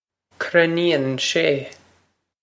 krin-een shay
This is an approximate phonetic pronunciation of the phrase.